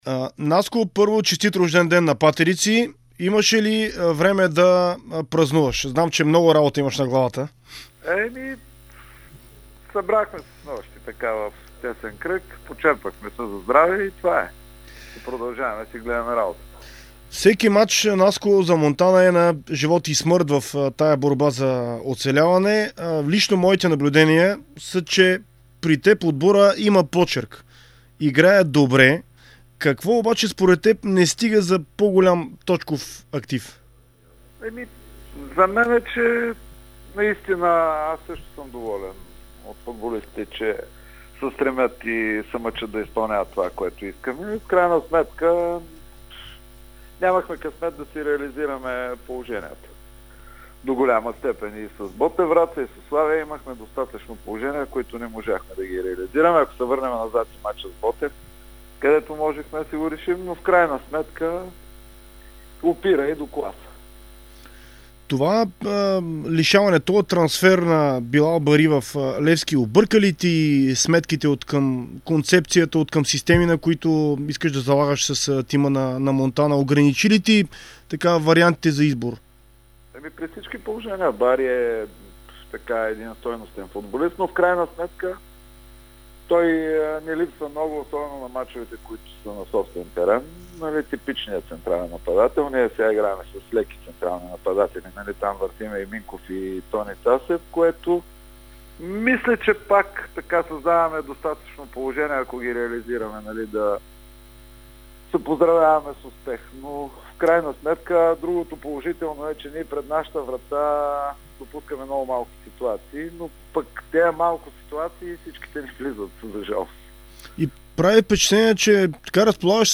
говори в спортното предаване на Дарик Варна за предстоящия мач срещу Черно море, за шансовете пред тима за оцеляване и за настоящия формат на първенството.